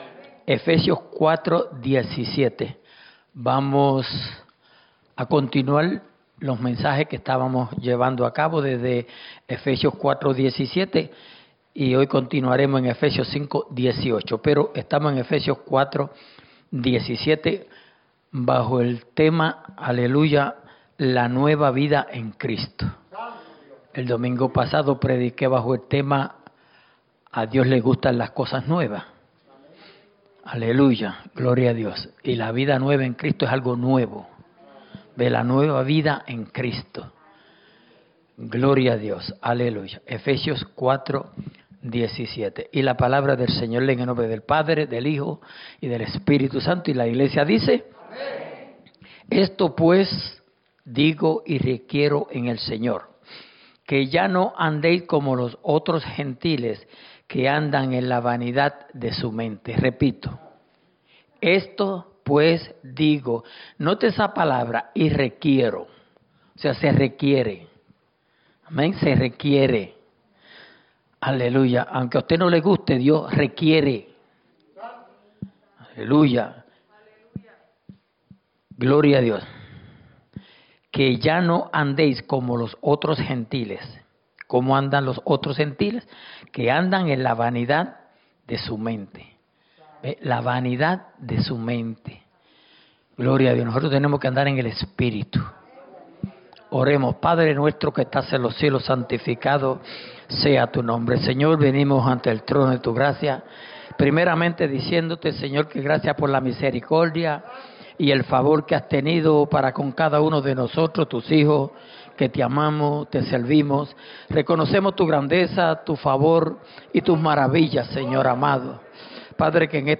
Grabado en la Iglesia Misión Evangélica en Souderto, PA